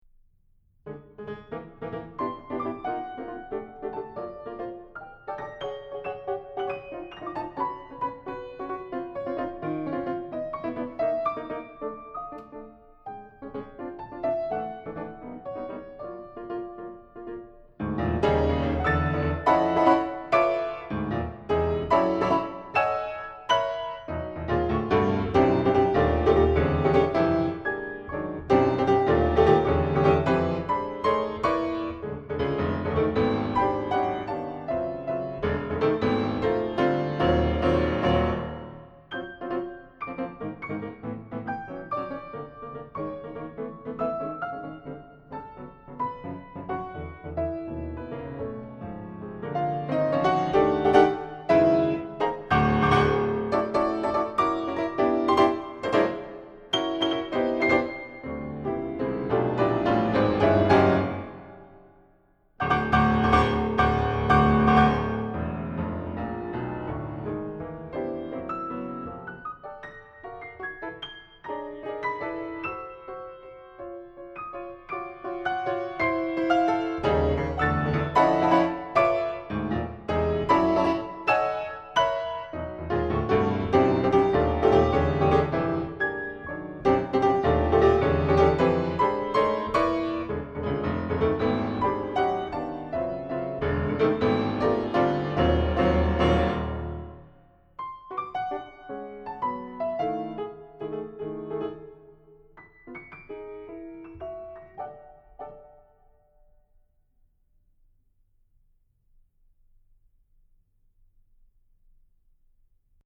Solo Piano
Sprite, for solo piano